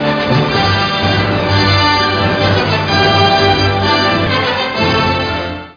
flourish.mp3